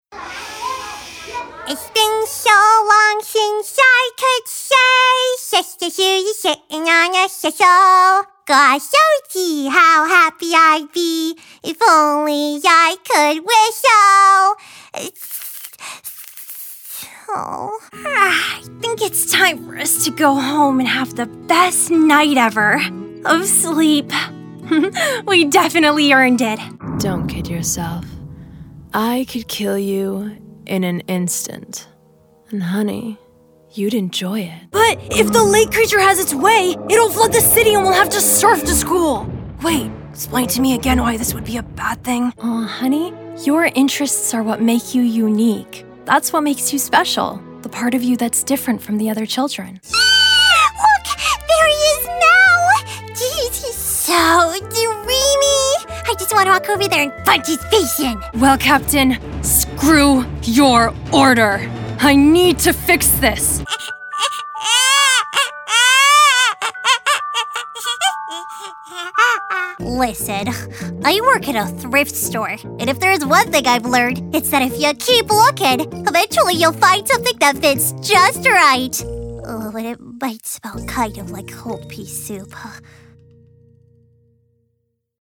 English - USA and Canada
Young Adult
Character Voice